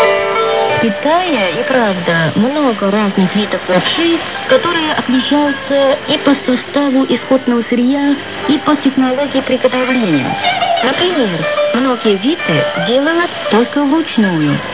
Belarus Shortwave set
Taking that into account, I think the PCB below contains quite a lot of coils still, but tests show that the radio is a single conversion superhet.
Like half a century ago, Russian language programs can be heard in the 31m band, but in 2024 they come from China.